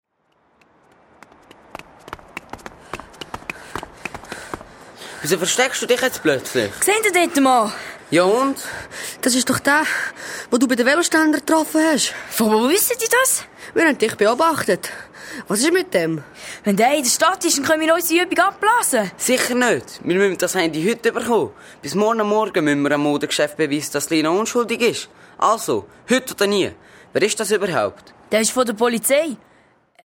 Das spannende Detektivhörspiel zum Thema Jugendgewalt greift die aktuelle Problematik auf, der viele Schülerinnen und Schüler ausgesetzt sind: Das Verständnis, dass das eigene Ansehen nur durch Markenkleider und Zurückschlagen gewahrt werden könne.